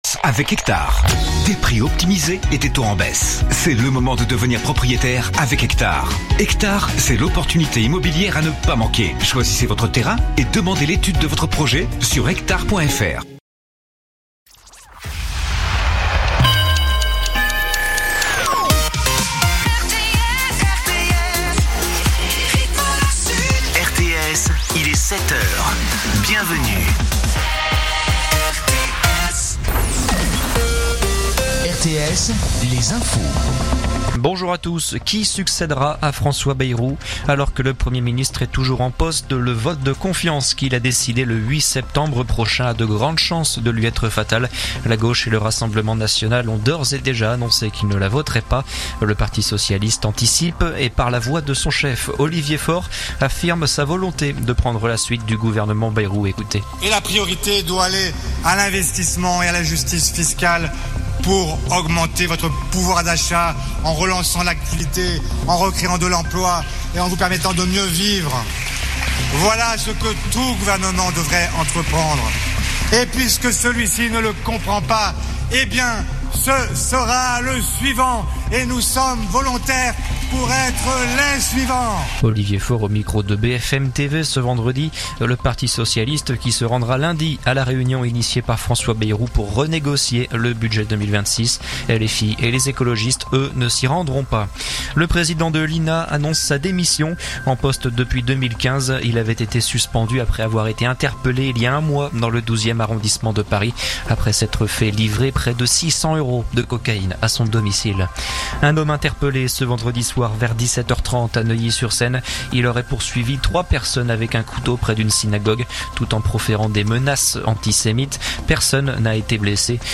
info_mtp_sete_beziers_491.mp3